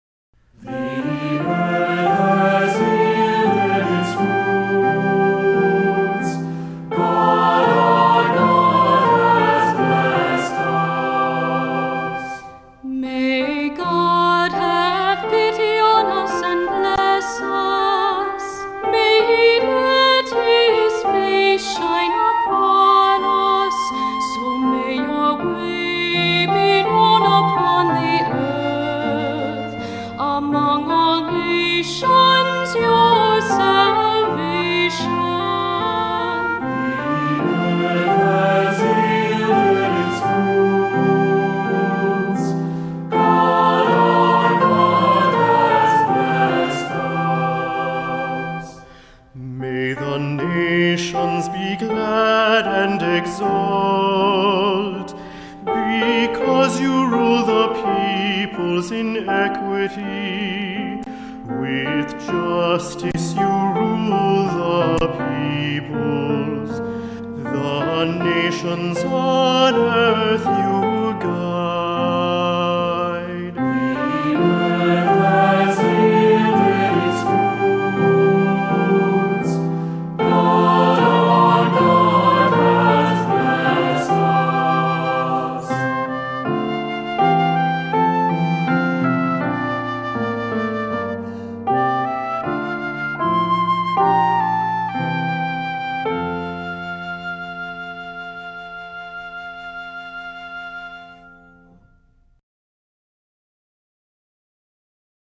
Voicing: "SATB", "Cantor", "Assembly"